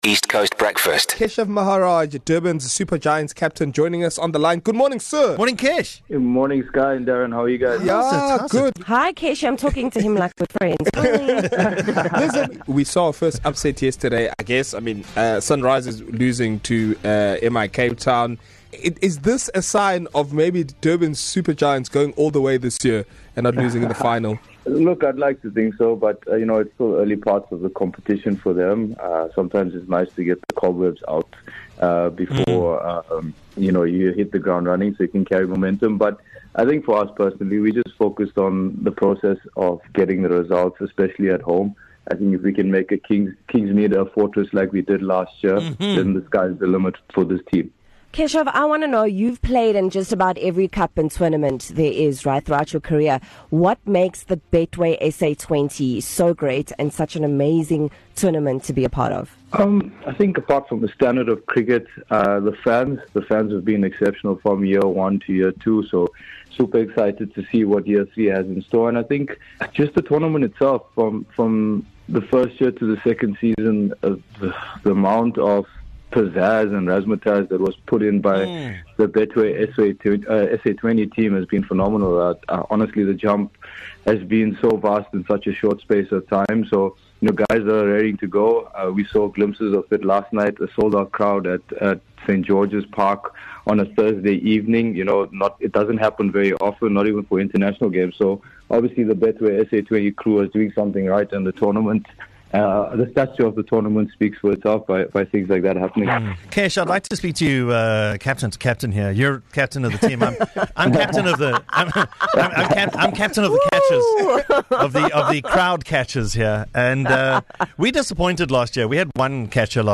Get an exclusive behind-the-scenes look into the Durban Super Giants’ journey in the Betway SA20 tournament as captain Keshav Maharaj joins East Coast Breakfast. Keshav shares his thoughts on the team’s strategy, their star-studded lineup, and the incredible support from Durban fans.